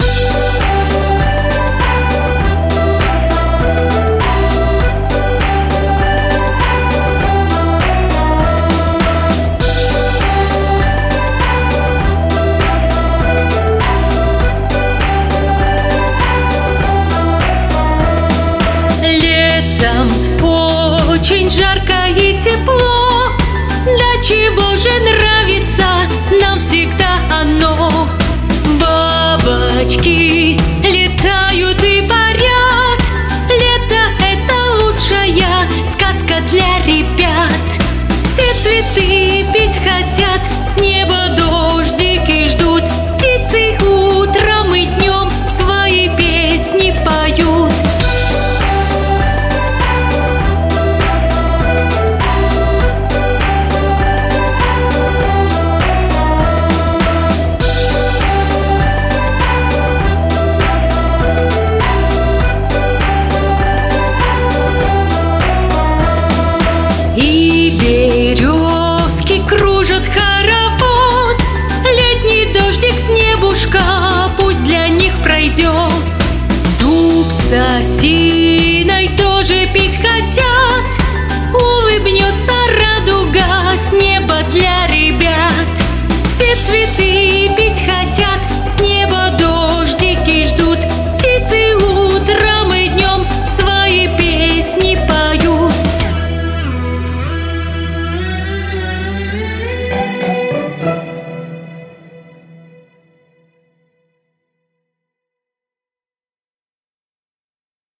Современные, новые, популярные песни для детей 👶👧